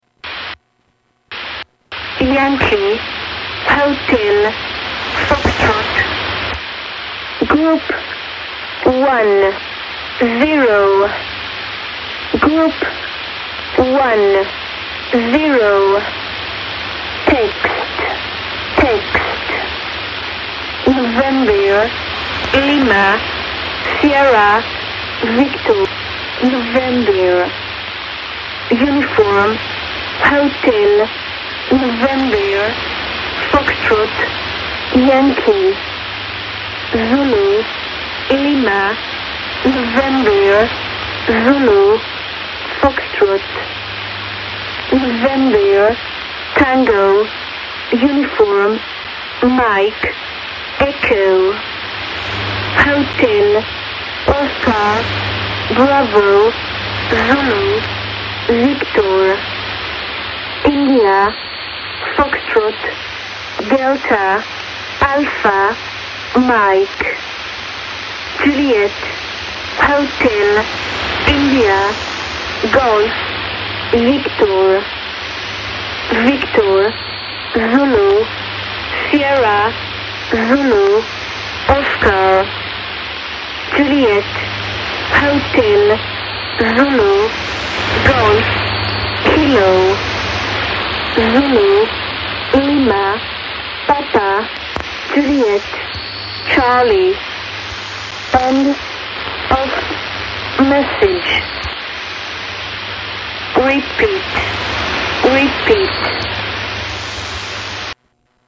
Tags: Radio Broadcast Secret Spy Broadcast NATO Number Station